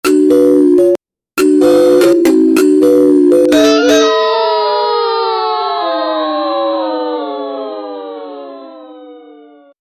Sound effect during 3 sec for a game when girls win : Girls Win
sound-effect-during-3-sec-mly6xl7e.wav